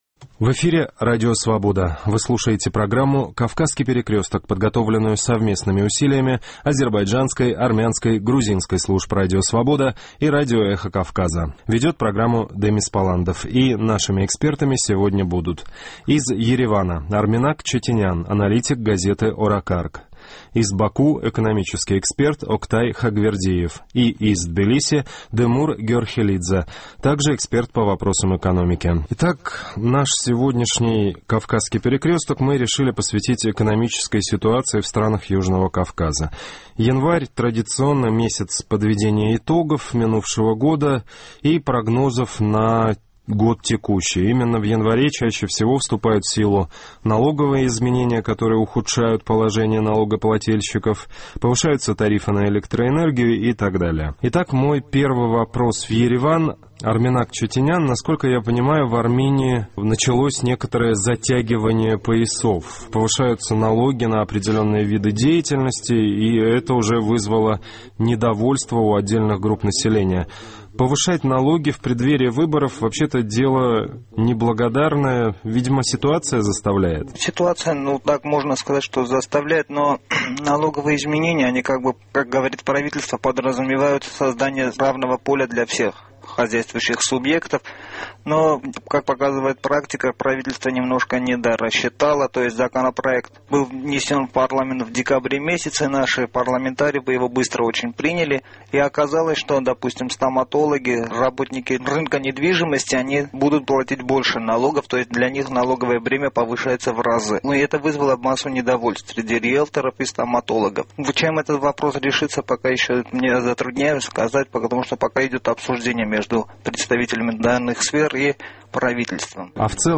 Очередной выпуск программы «Кавказский перекресток» посвящен экономической ситуации в странах Южного Кавказа. Эксперты подводят итоги прошедшего года и дают прогнозы на будущее.